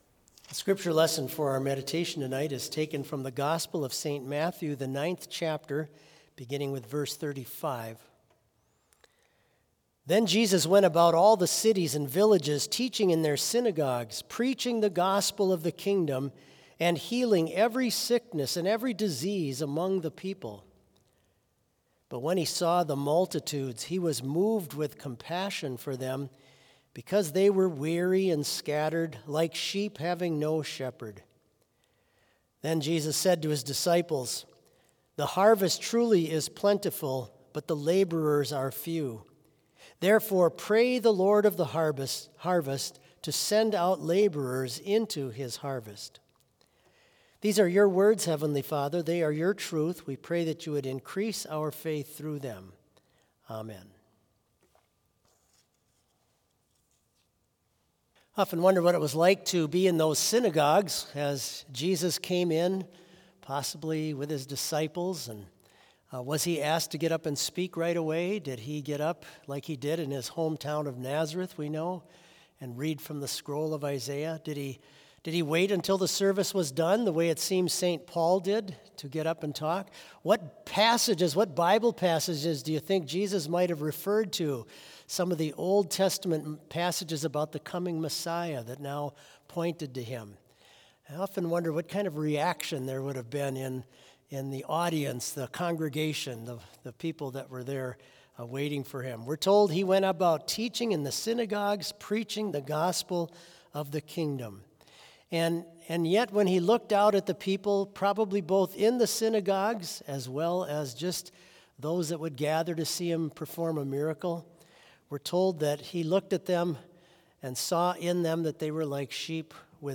Vespers worship service held on January 29, 2025, BLC Trinity Chapel, Mankato, Minnesota
Complete service audio for Vespers - Wednesday, January 29, 2025